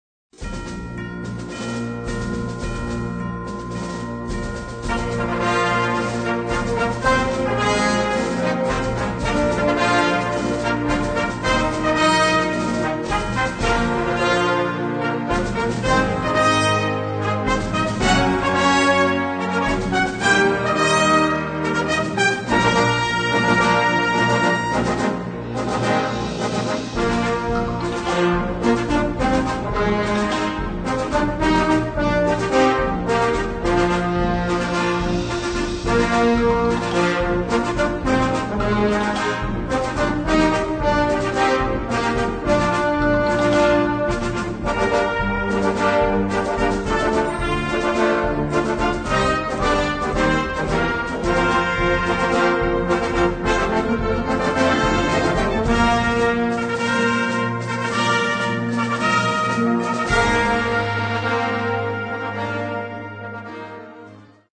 Gattung: Eröffnungsfanfaren
Besetzung: Blasorchester